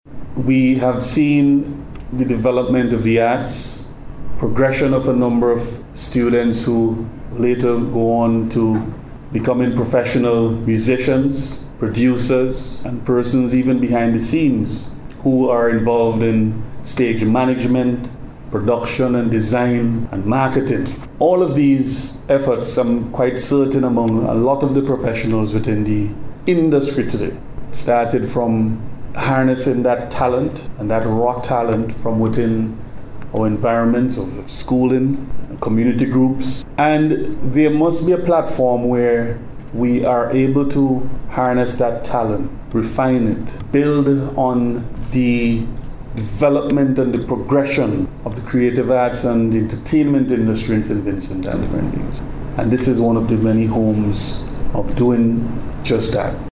He spoke on the issue, as he delivered remarks yesterday at the media launch for the 2023 National School Bands showcase.